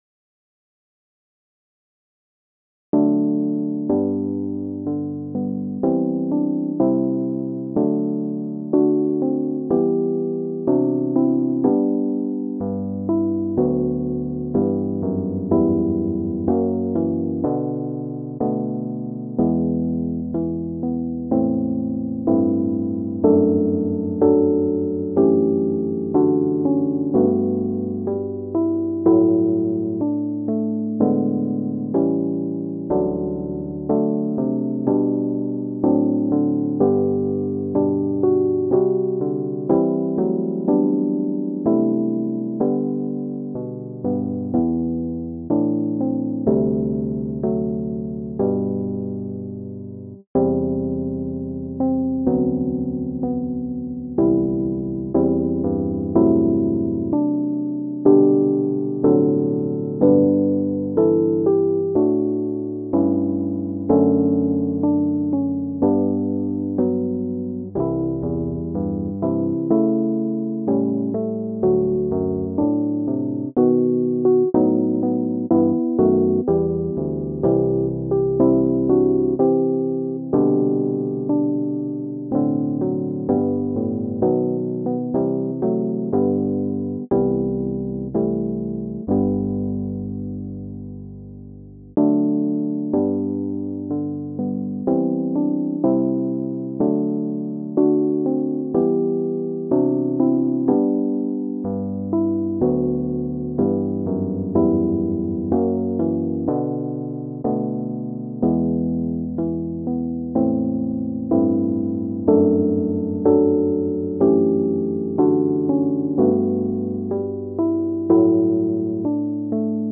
Click the Button to sing the hymn in G, or Pray the prayer music in a New Window